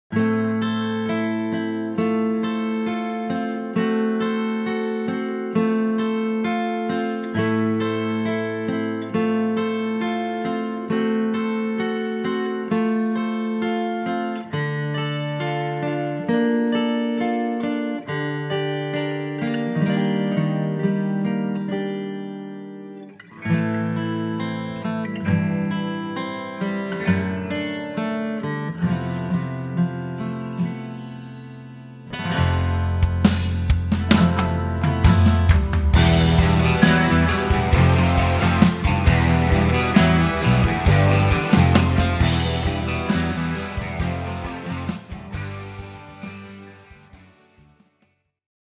Waghorn Hydra acoustic (maple back and sides with cutaway)